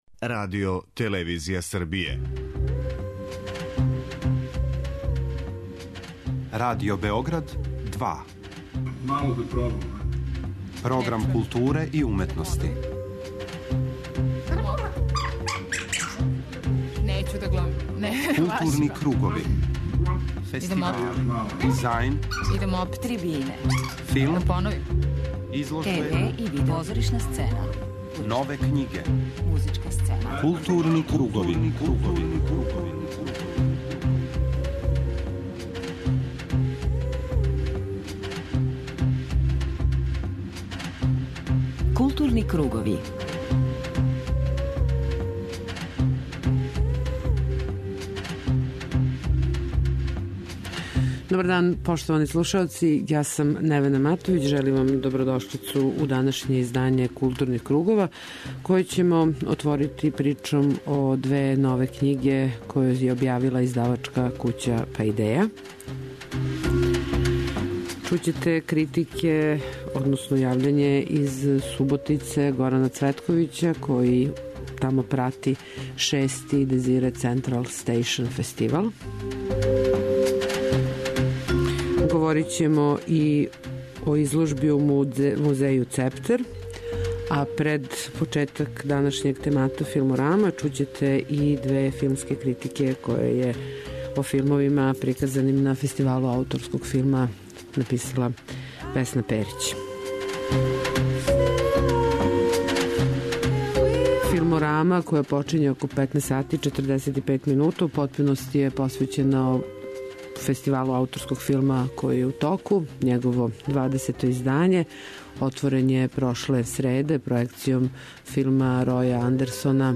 У првом делу емисије бележимо најзначајније догађаје из културе, док ћете у 'Филморами' слушати интервјуе снимљене на 20. Фестивалу ауторског филма, који је у току.